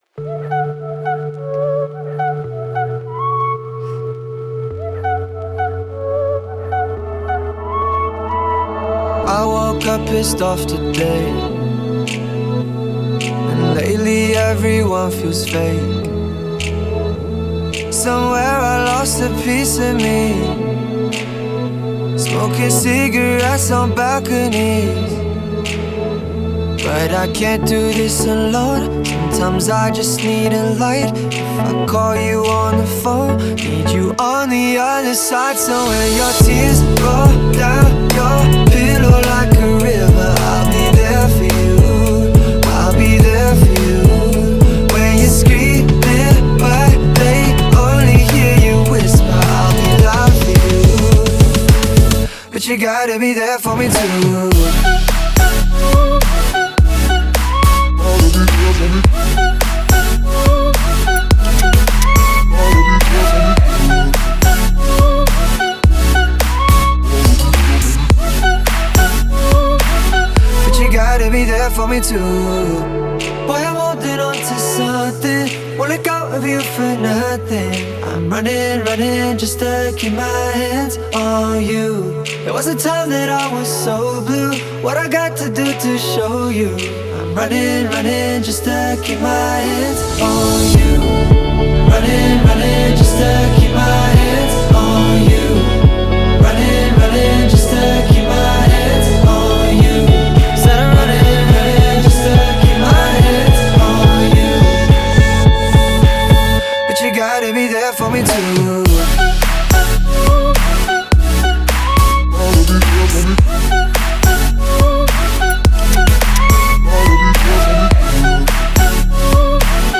BPM106
MP3 QualityMusic Cut